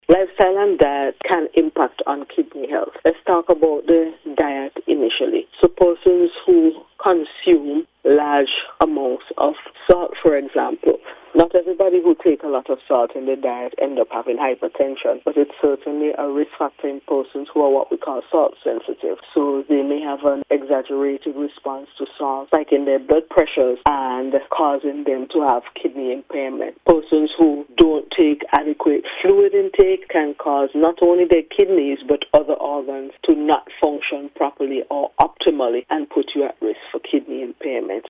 In a recent interview with NBC News